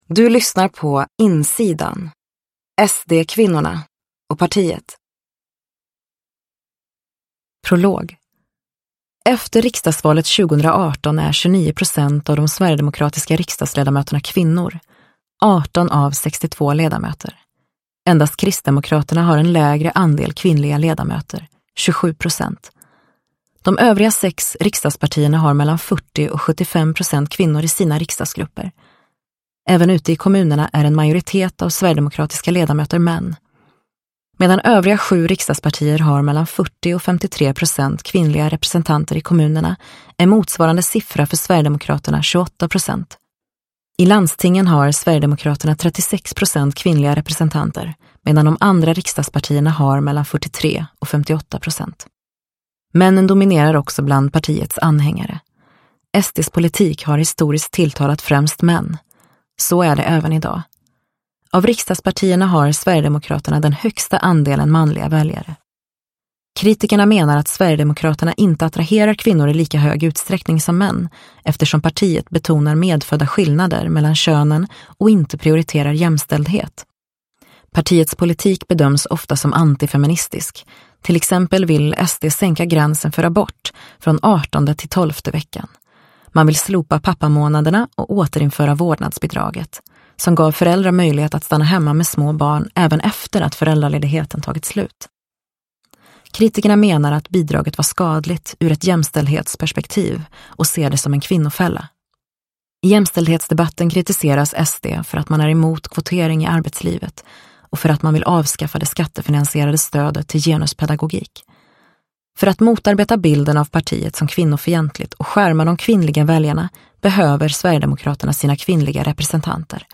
Insidan : SD-kvinnorna och partiet – Ljudbok – Laddas ner